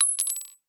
bullet-metal-casing-drop-2.mp3